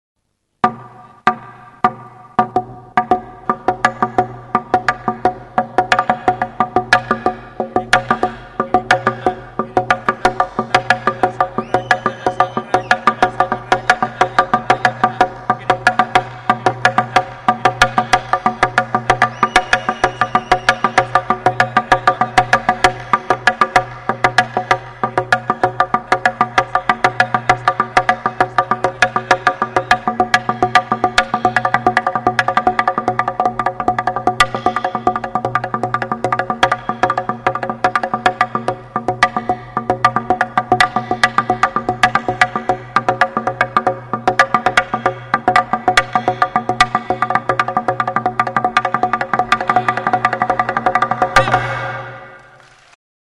Idiophones -> Frappés -> Directement
KIRIKOKETA JOALDIA. Baztango 'jo ala jo' kirikoketa taldea. Hernani, 1999.